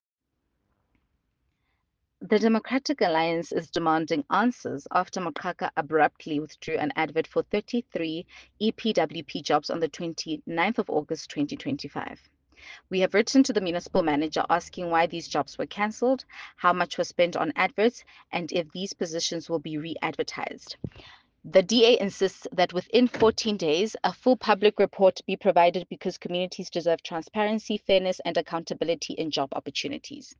English and Sesotho soundbites by Cllr Mbali Mnaba.